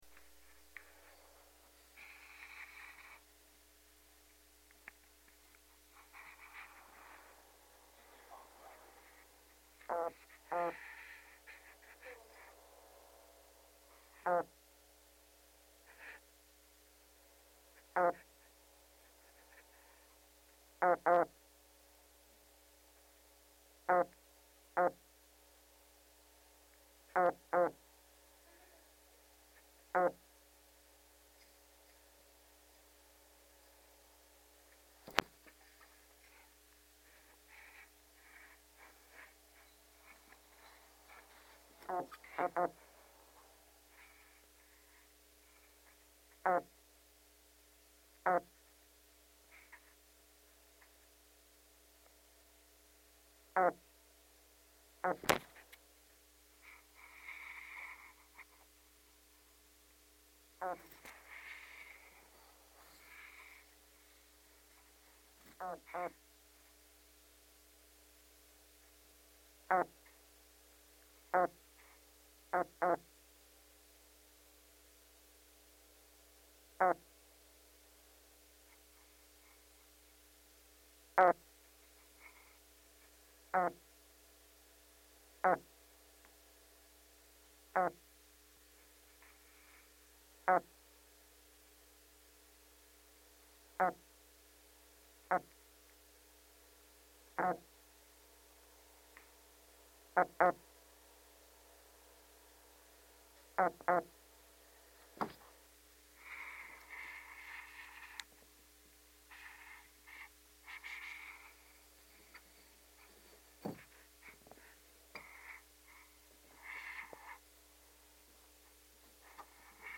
花身鯻 Terapon jarbua
高雄市 新興區 哨船頭公園
錄音環境 保麗龍箱中